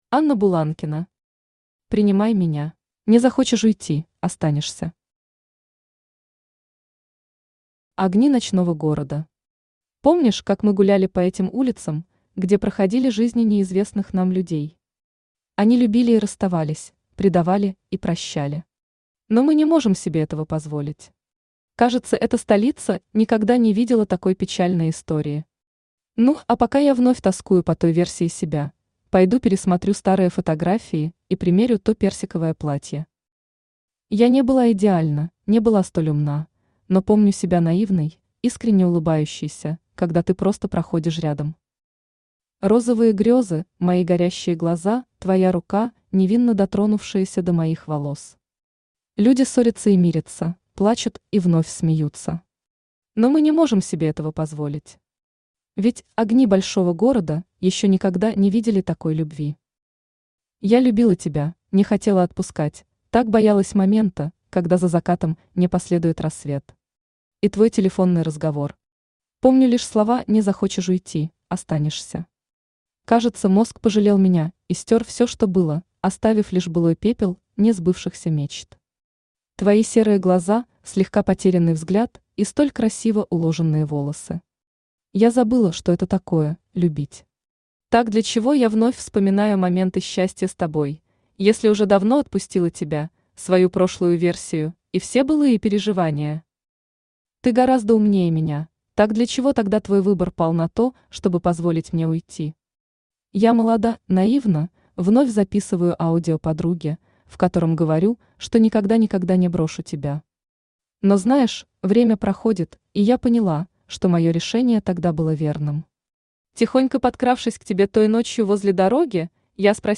Aудиокнига Принимай меня Автор Анна Сергеевна Буланкина Читает аудиокнигу Авточтец ЛитРес. Прослушать и бесплатно скачать фрагмент аудиокниги